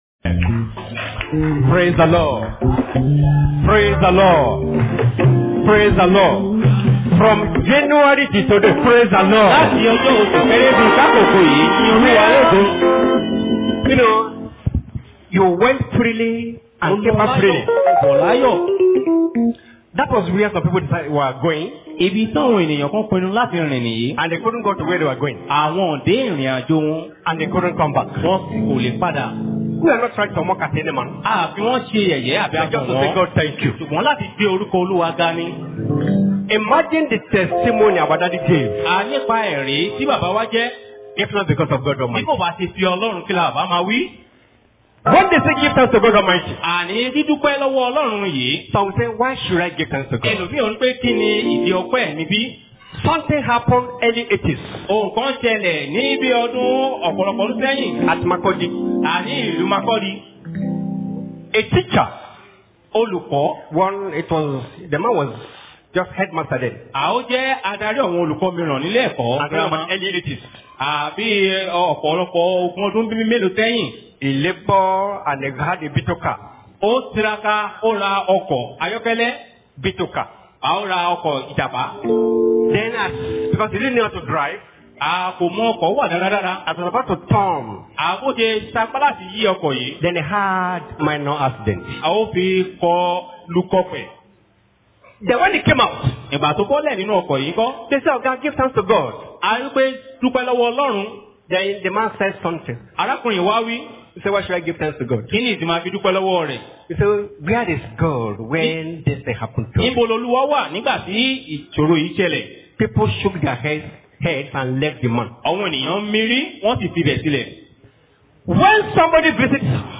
Thanksgiving Service